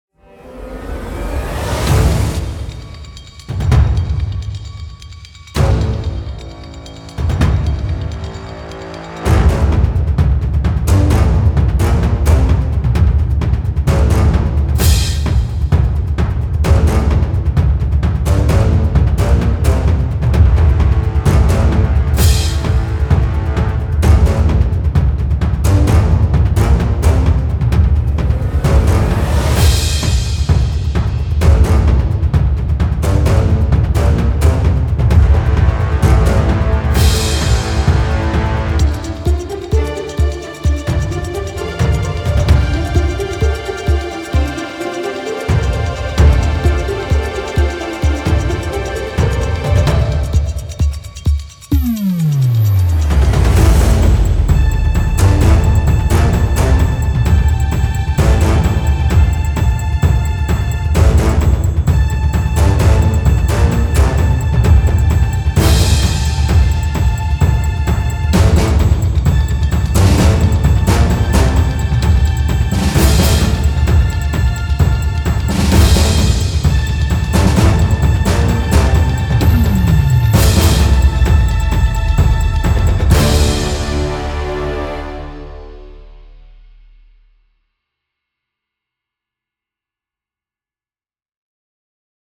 Genre: DRAMA, DANGER, PERIL.